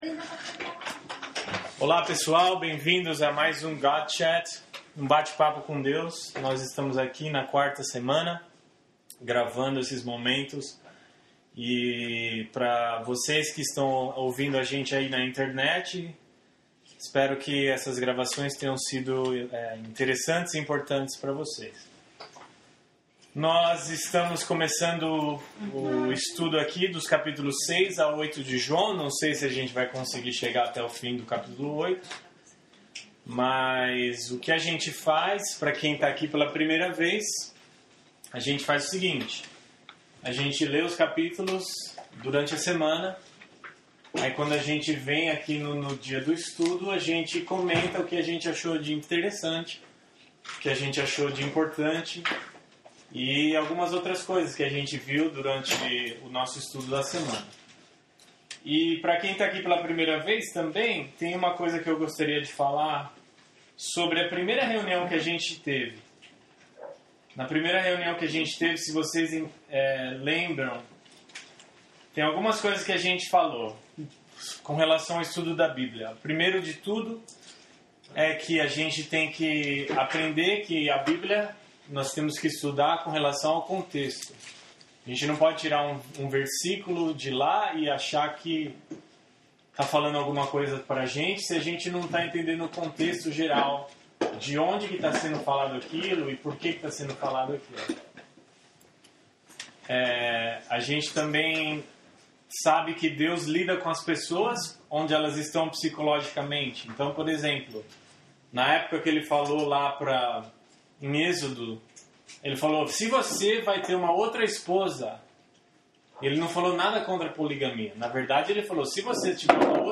O audio de nosso diálogo está abaixo, e o guia de estudo também pode ser baixado aqui.